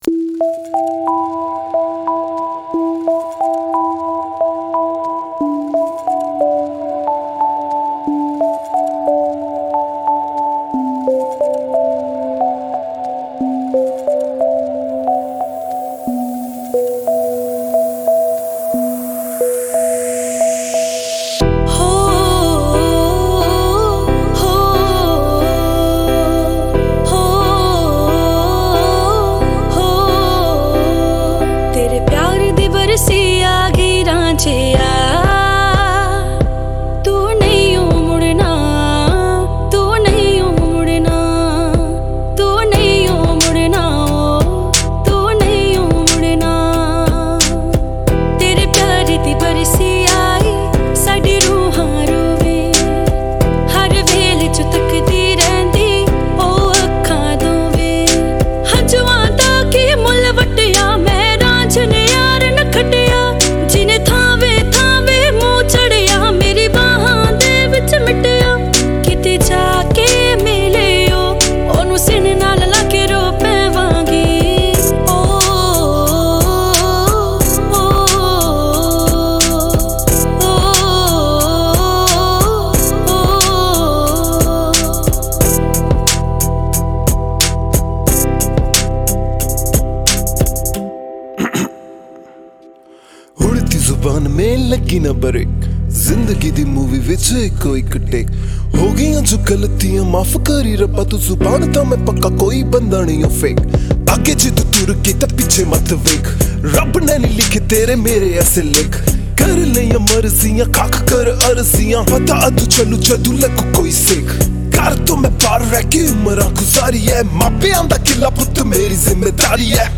More Songs From Punjabi Mp3 Songs